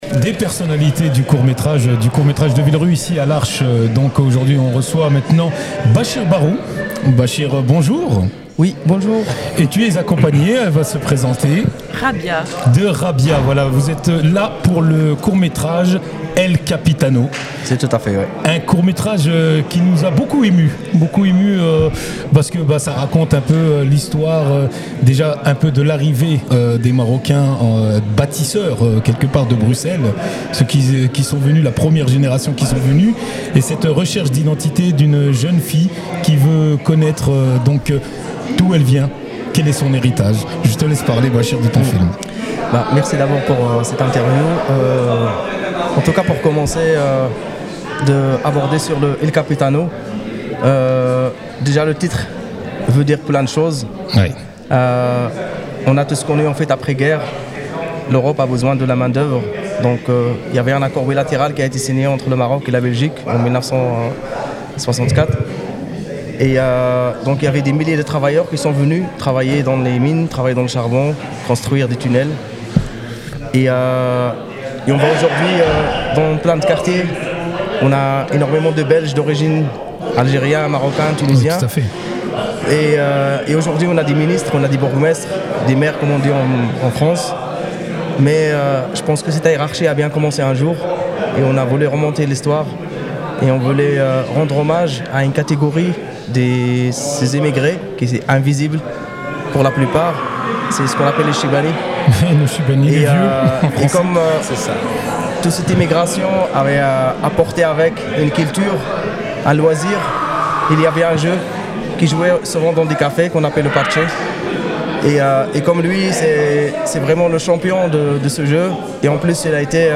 Boîte à images (Interviews 2025)